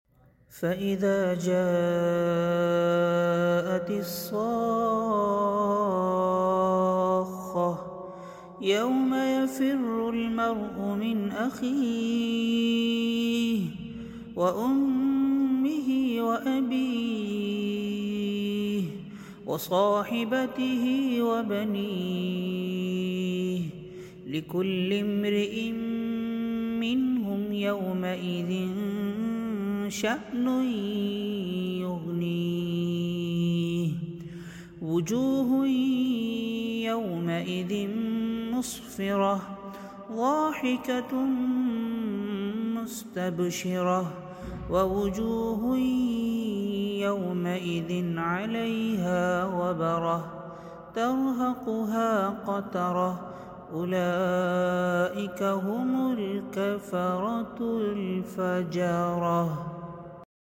SURAH-ABASA today quran Recitation By